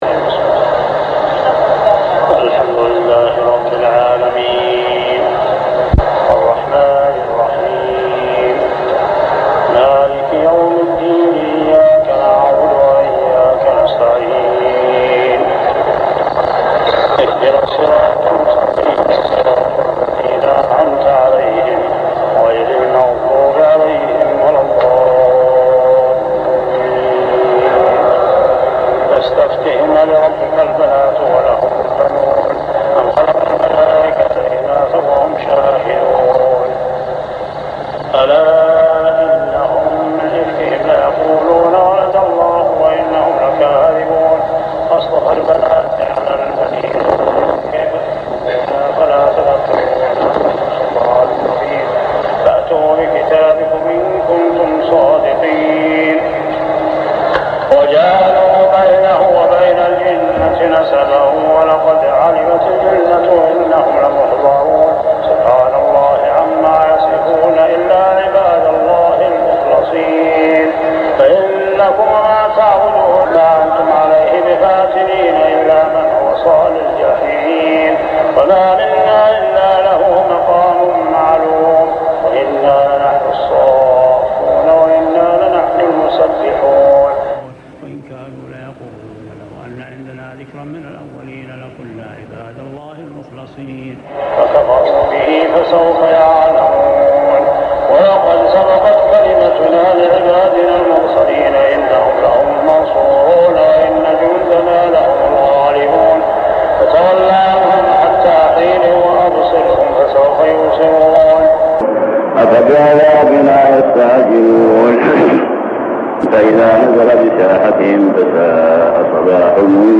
صلاة تراويح عام 1399هـ سور الصافات 149-182 و صٓ كاملة و الزمر 1-31 | Tarawih Prayer Surah As-Saffat, Sad, Az-Zumar > تراويح الحرم المكي عام 1399 🕋 > التراويح - تلاوات الحرمين